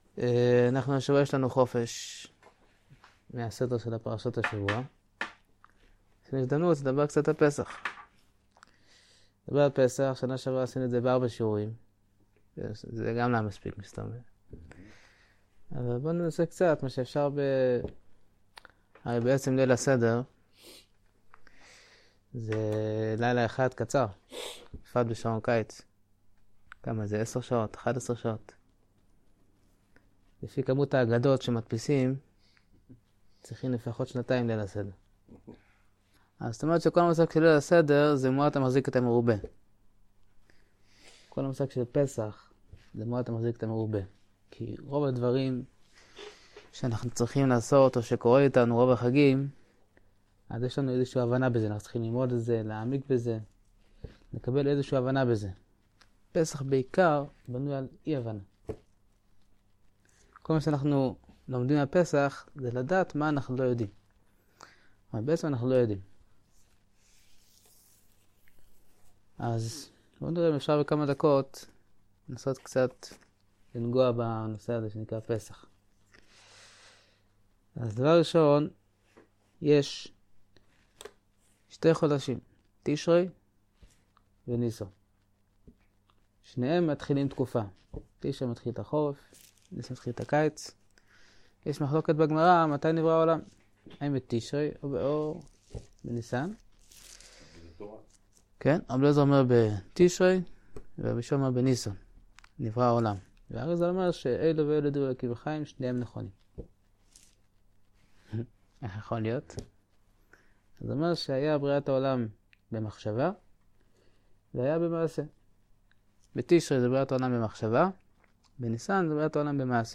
שיעור 63 פסח
שיעור-63-פסח.mp3